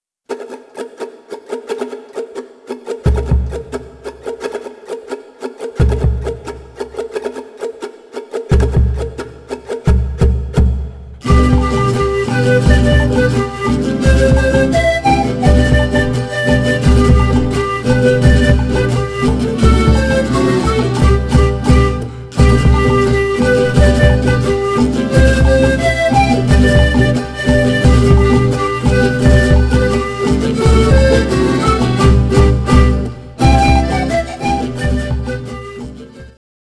（インストゥルメンタル：アンデス地方伝承曲）